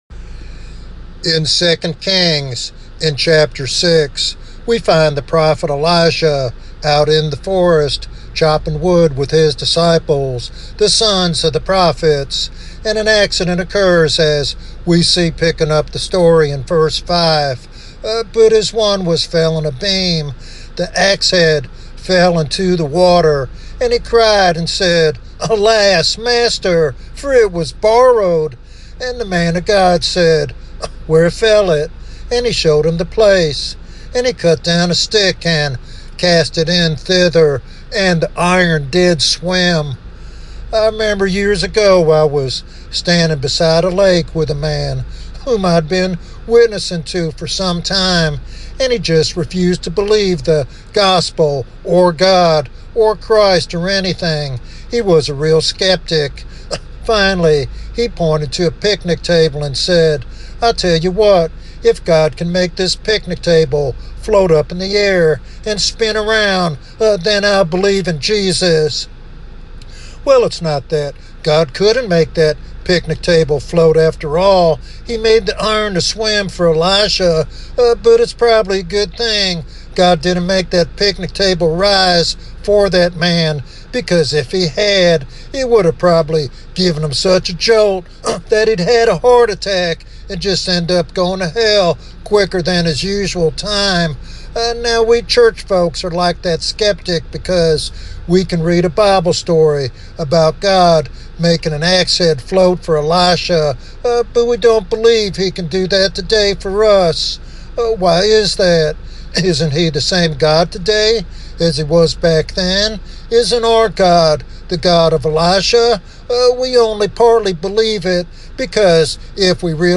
In this devotional sermon